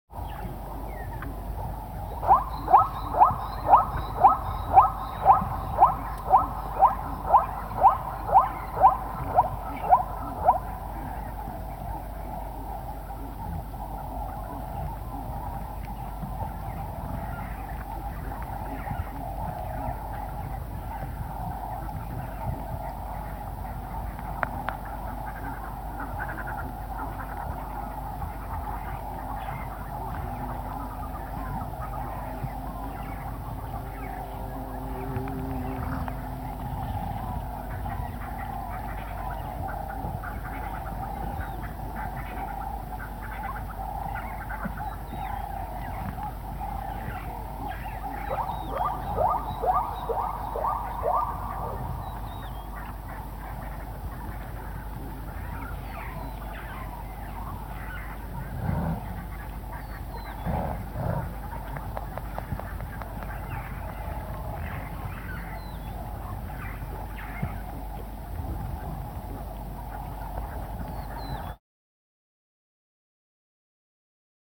جلوه های صوتی
دانلود آهنگ گورخر 7 از افکت صوتی انسان و موجودات زنده
دانلود صدای گورخر 7 از ساعد نیوز با لینک مستقیم و کیفیت بالا